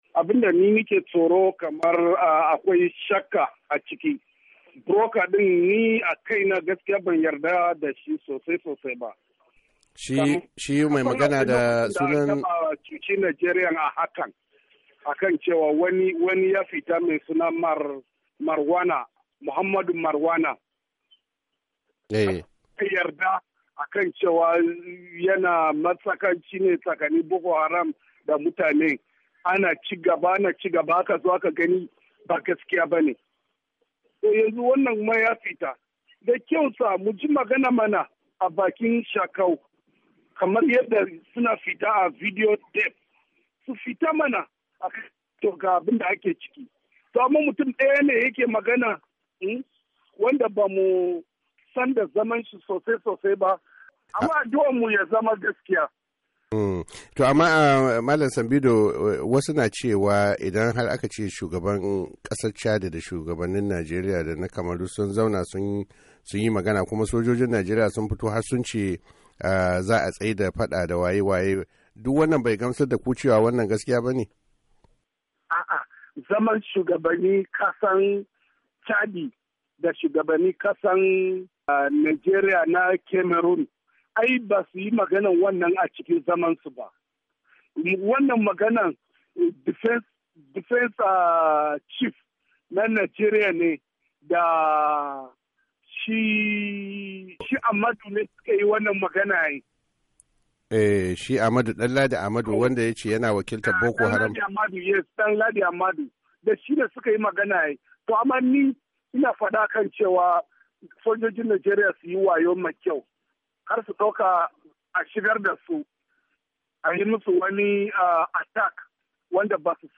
yayi cikakken bayani kan dalilan su na yin shakku da tababa a wata tattaunawa